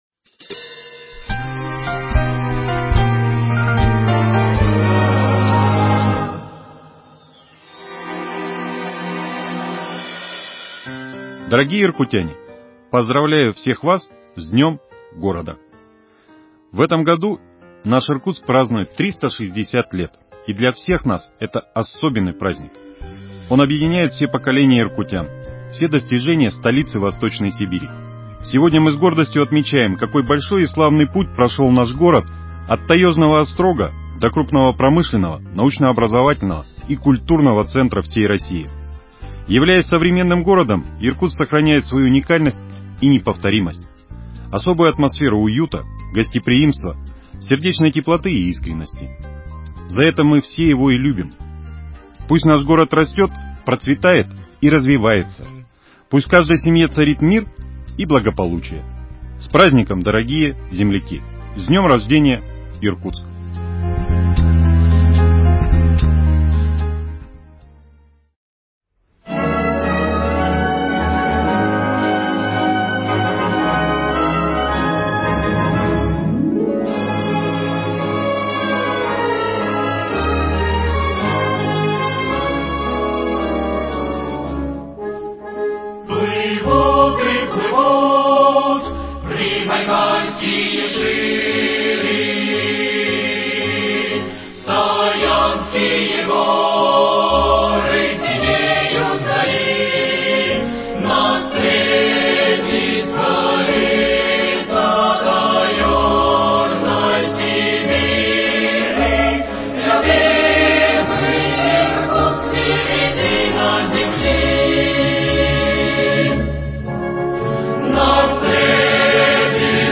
Поздравление мэра Руслана Болотова в честь Юбилея – 360-летия Иркутска.